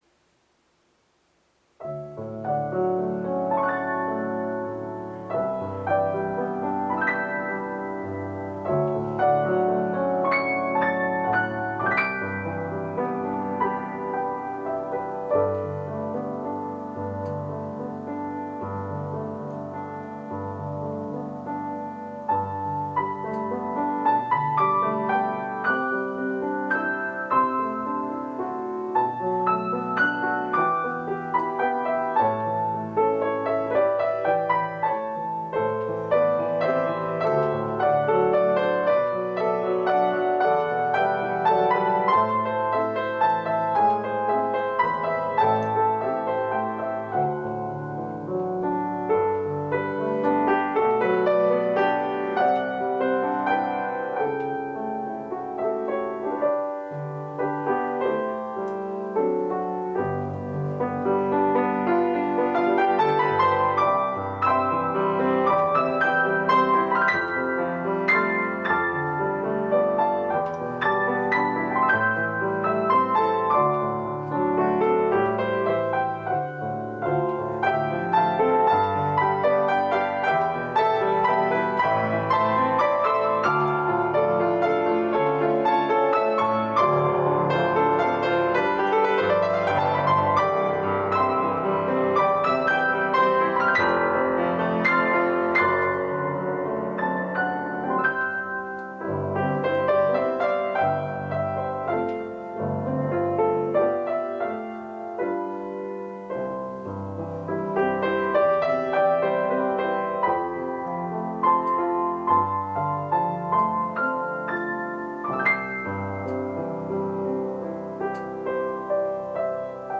piano notes